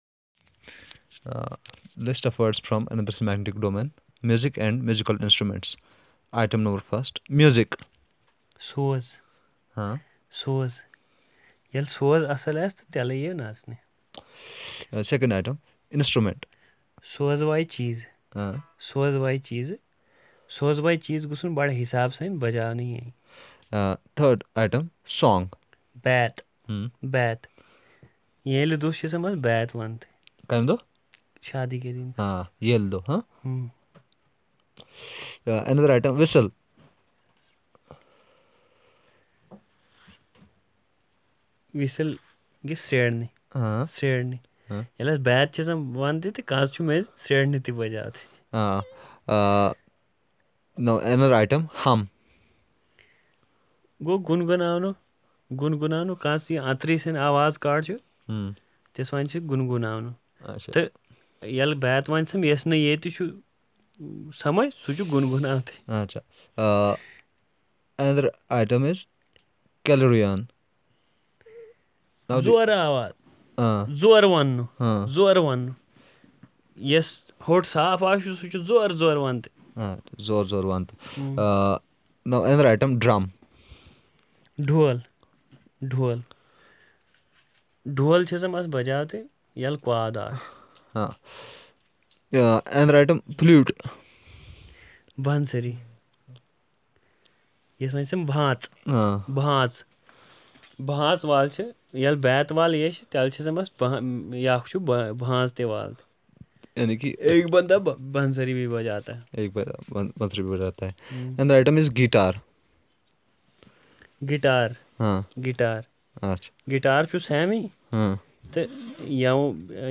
Elicitation of words about music and musical instruments, sports, games and entertainment